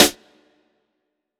TC SNARE 21.wav